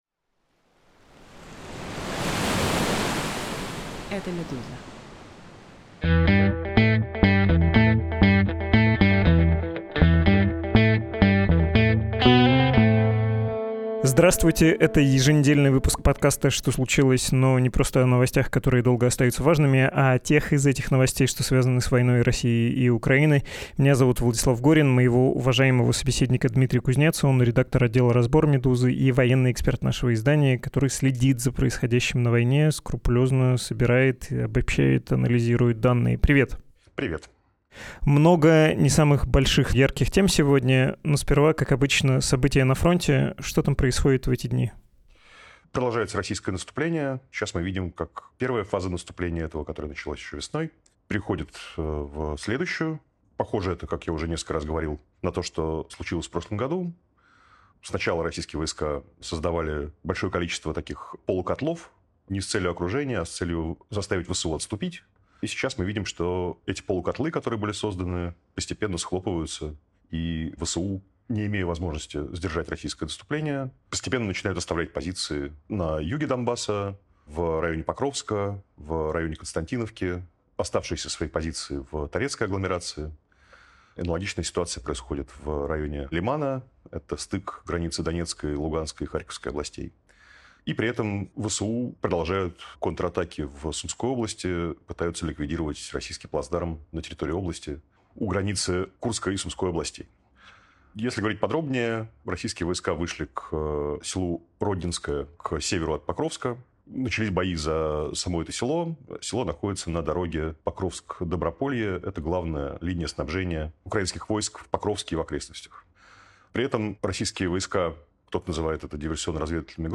«Что случилось» — новостной подкаст «Медузы».